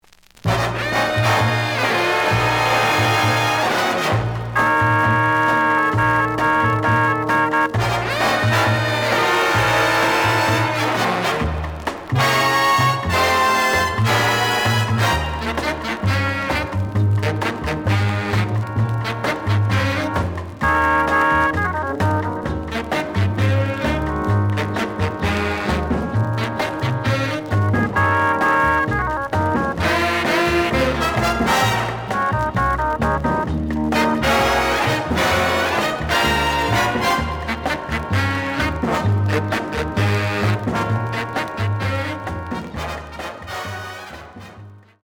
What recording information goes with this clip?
The audio sample is recorded from the actual item.